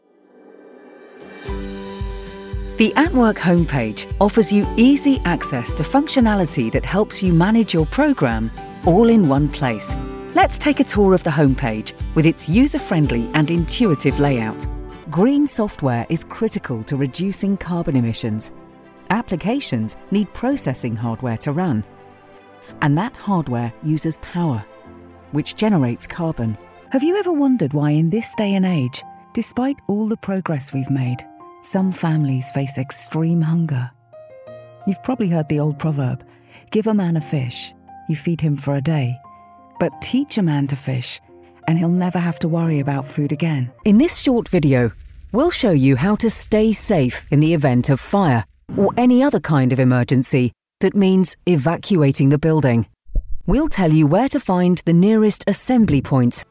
English (British)
Corporate Videos